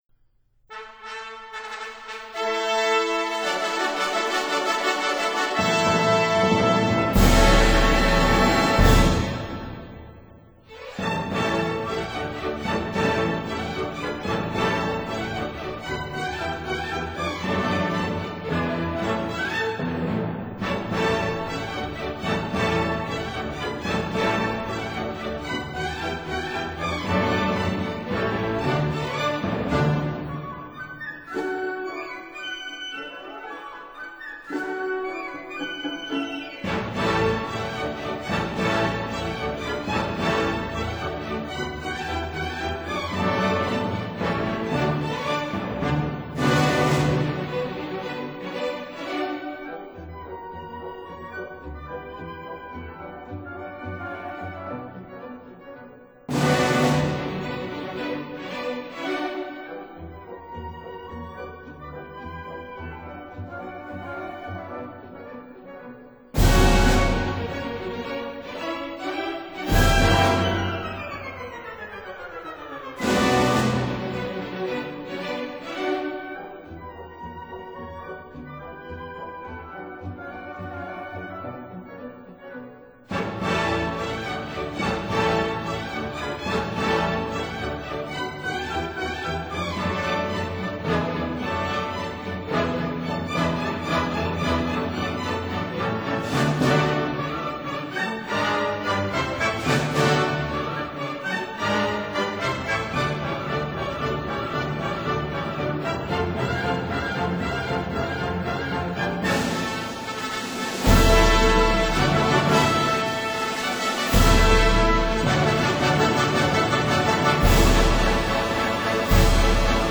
•Fantaisie, for Cello and Orchestra
Suite for Orchestra No. 4